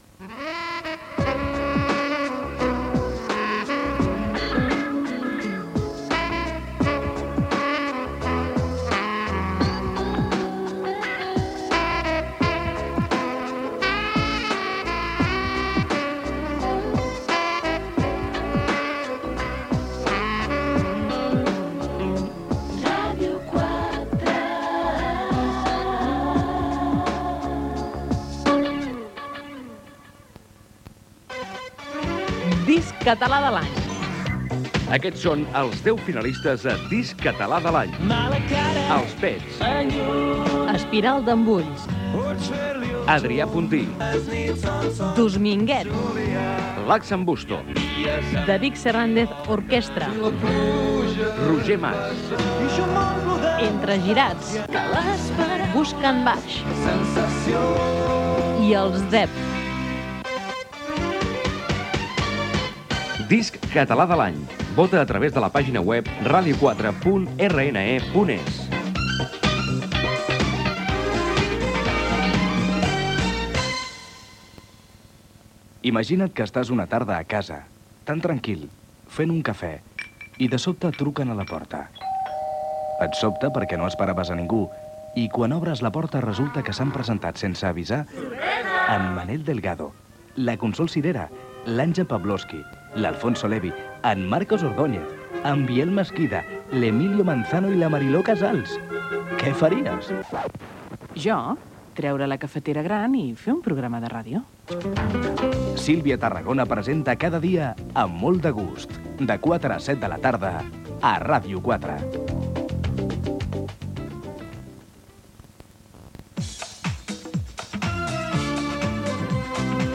Indicatiu de l'emissora, discs que participen al Disc Català de l'any, promoció del programa "Amb molt de gust", indicatiu de l'emissora amb algunes de les seves freqüències
FM